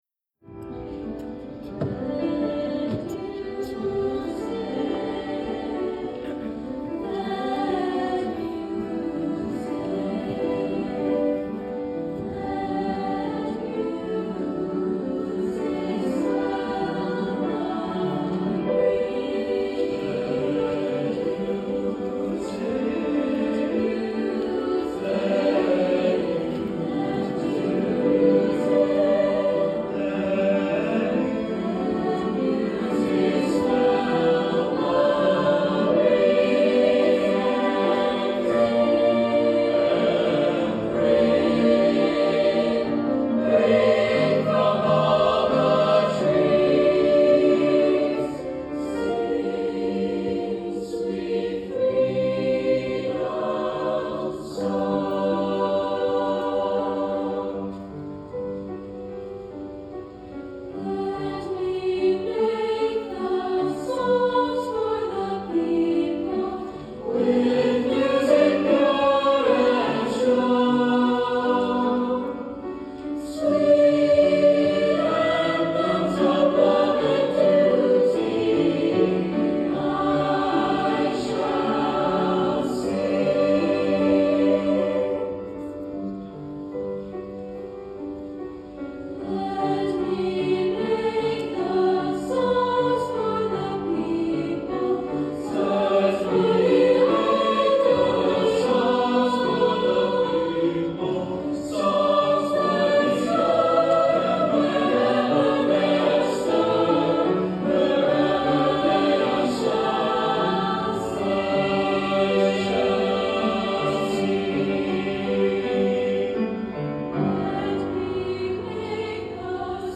SSATB + Piano 3’30”
SSATB, Piano